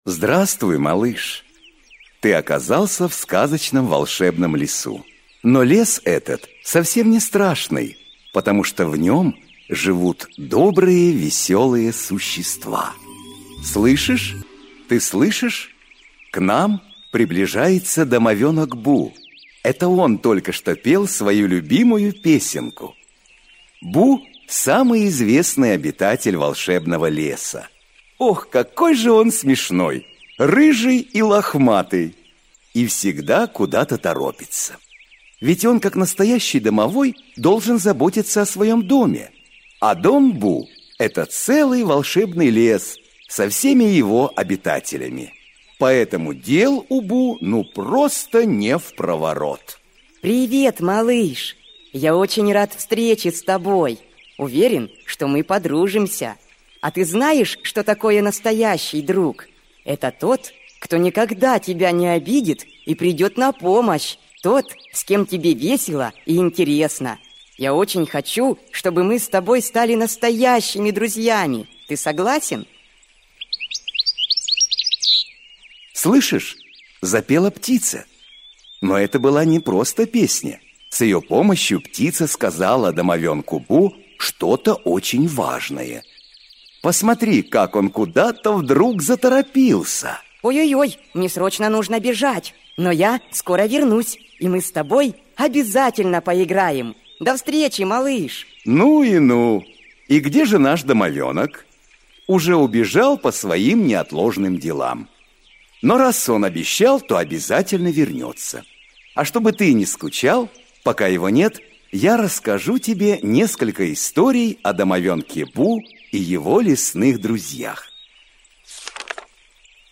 Аудиокнига Хочу Все Знать. Учимся хорошим манерам | Библиотека аудиокниг